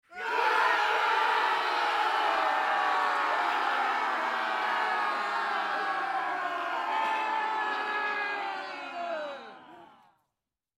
Здесь вы найдете громкие призывы воинов, ритуальные возгласы и современные мотивационные крики.
Группа людей подбадривает криками